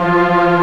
Index of /90_sSampleCDs/Giga Samples Collection/Organ/MightyWurltzBras